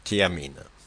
Ääntäminen
Synonyymit vitamine B Ääntäminen France (Paris): IPA: /tja.min/ Haettu sana löytyi näillä lähdekielillä: ranska Käännöksiä ei löytynyt valitulle kohdekielelle.